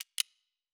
Sound / Effects / UI